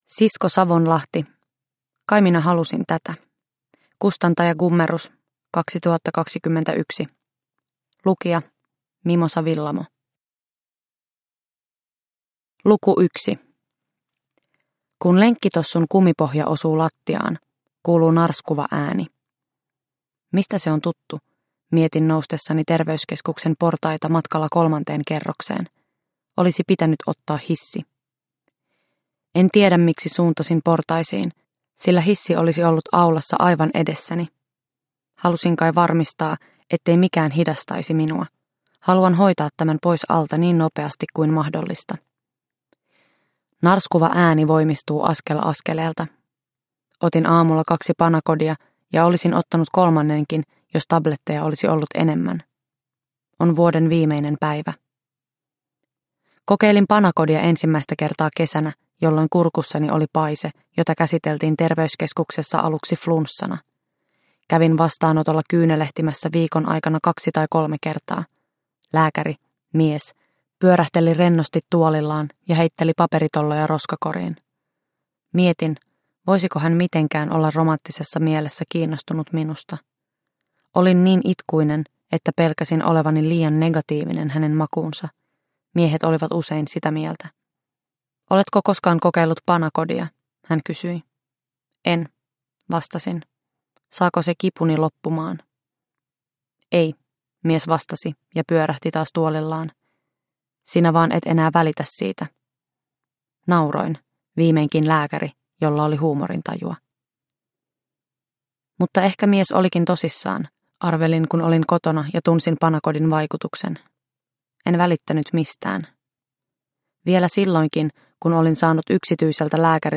Kai minä halusin tätä – Ljudbok – Laddas ner